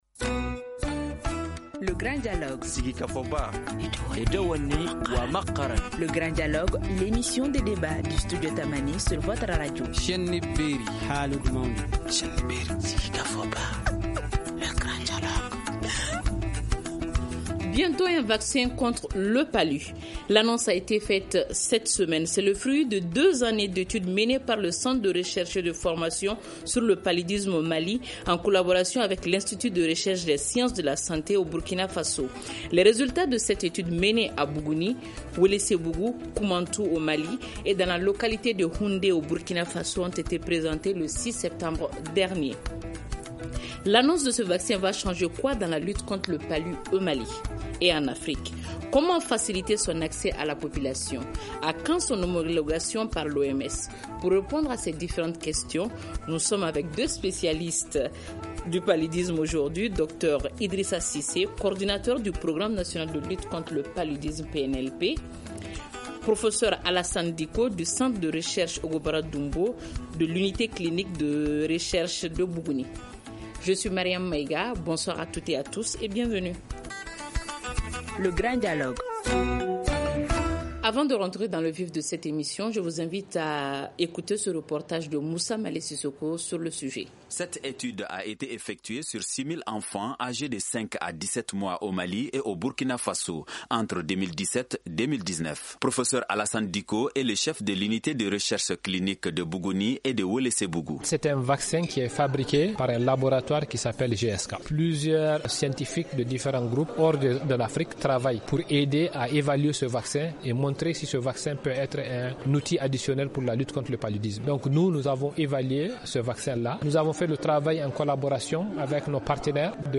Pour répondre à ces questions, nos invités sont :